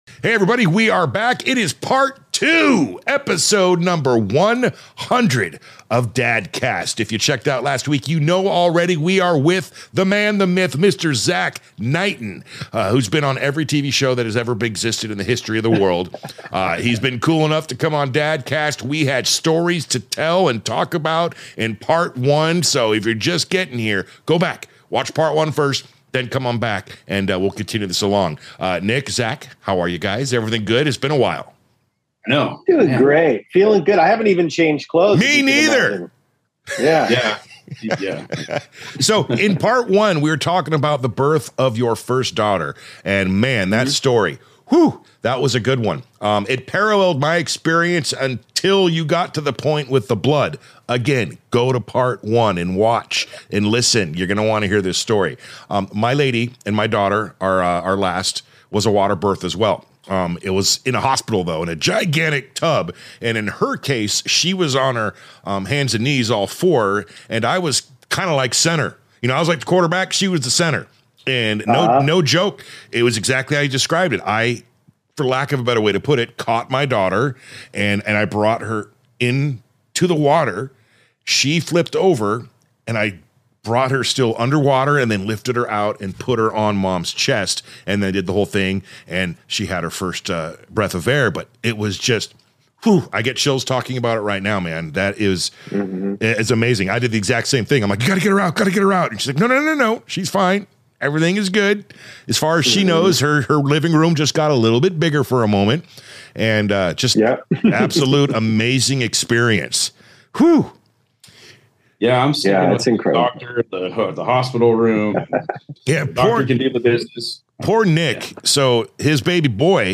Currently starring in NBC's Magnum PI, we sit down and chat with this amazing father for our celebratory 100th episode!